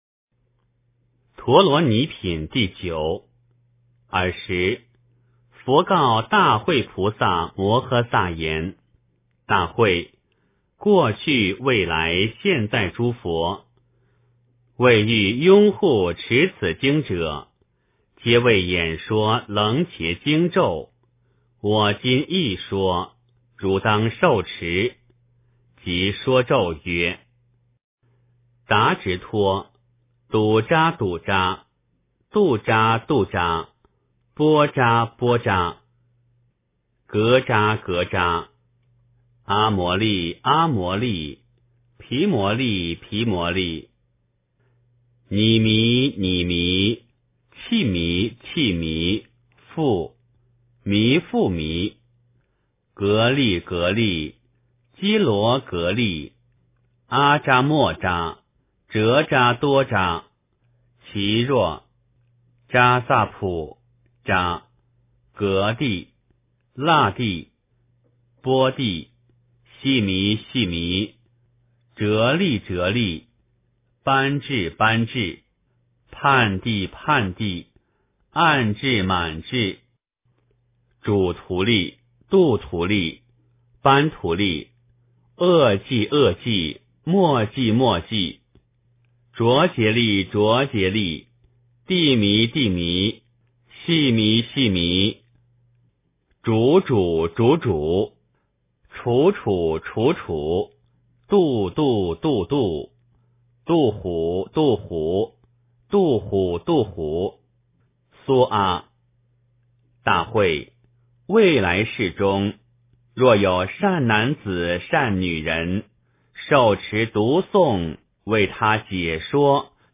楞伽经（陀罗尼品第九） 诵经 楞伽经（陀罗尼品第九）--未知 点我： 标签: 佛音 诵经 佛教音乐 返回列表 上一篇： 楞伽经（如来无常品第五） 下一篇： 楞伽经（偈颂品第十之二） 相关文章 貧僧有話33說：我的发心立愿--释星云 貧僧有話33說：我的发心立愿--释星云...